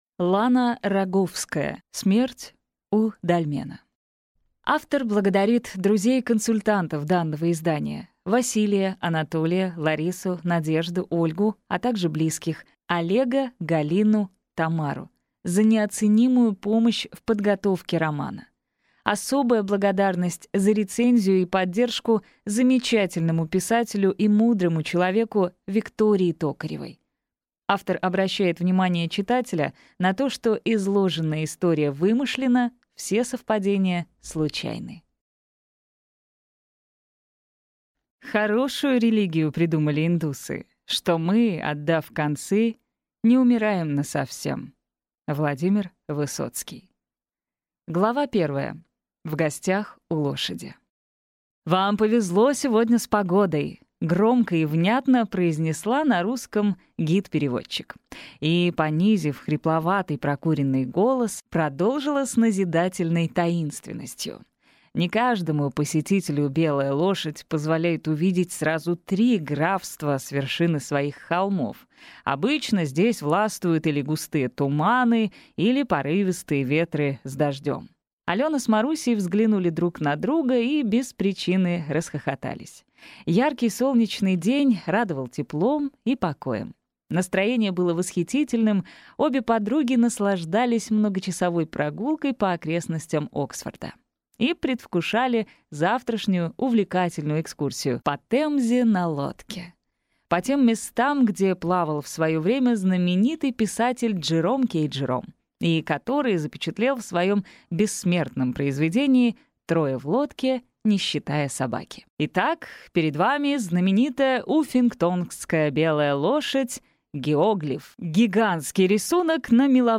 Аудиокнига Смерть у дольмена | Библиотека аудиокниг